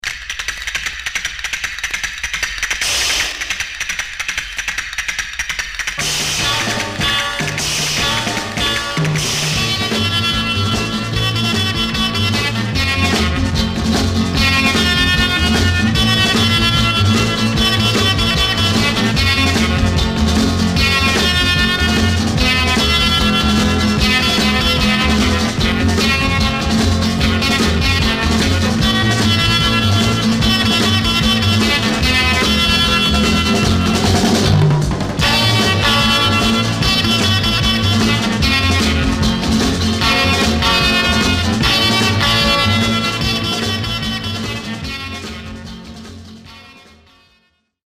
Mono
R & R Instrumental